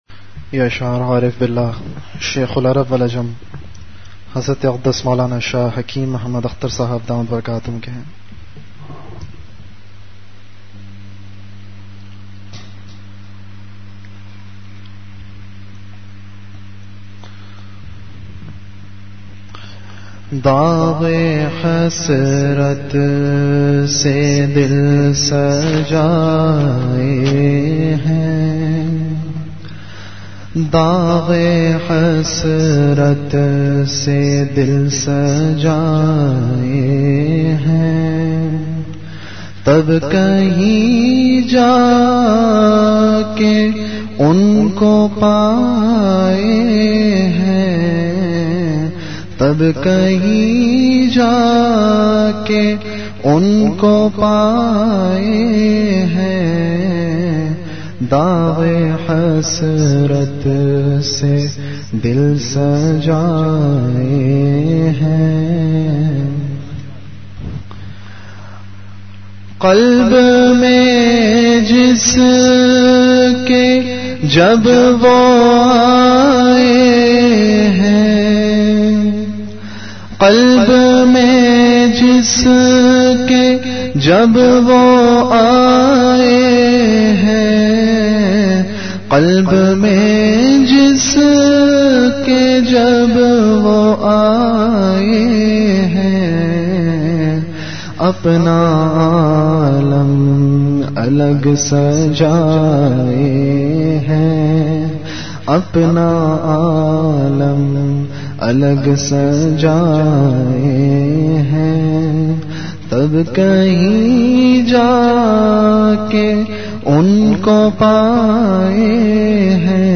An Islamic audio bayan
Delivered at Home.